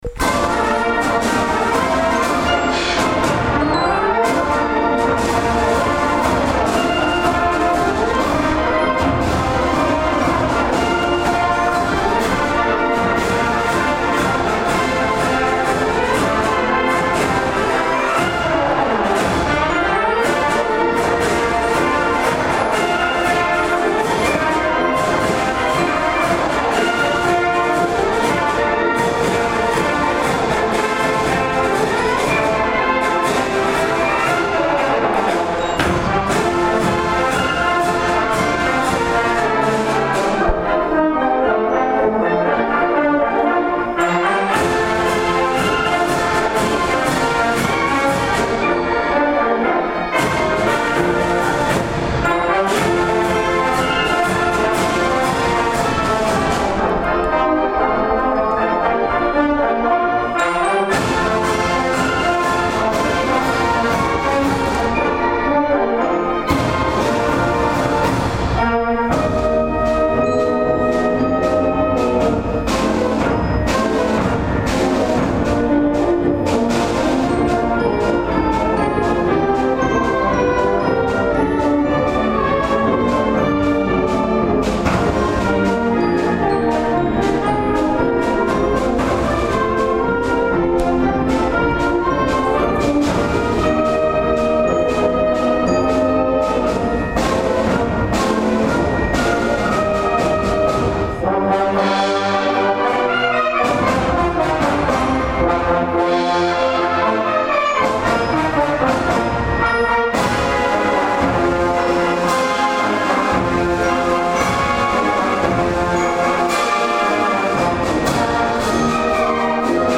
The third and final indoor concert for the 2025 season by the Karl L. King Municipal Band was held on Sunday afternoon, April 27th at 3:30 p.m. in the Fort Dodge Middle School Auditorium.
The program began with Henry Fillmore's march King Karl King.